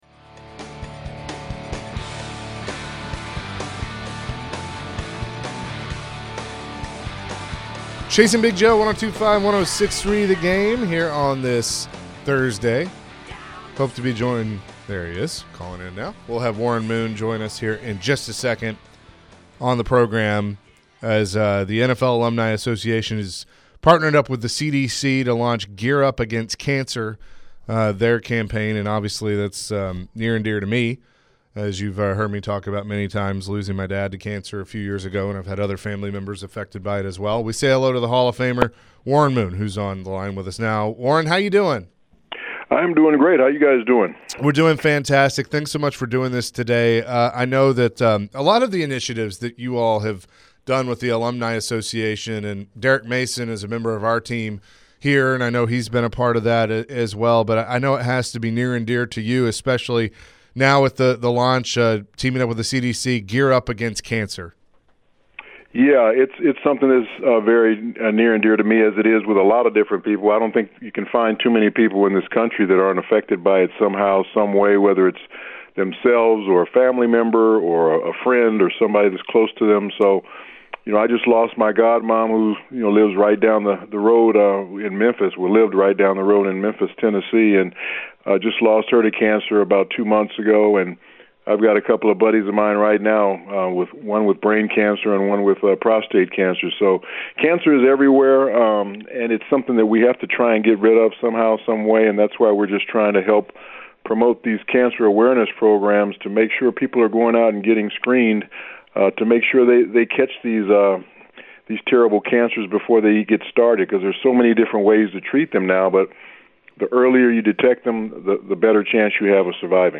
Pro Football Hall of Famer Warren Moon joined the show on behalf of the NFL Alumni association. Warren was asked about the modern-day game of football TItans, and Will Levis.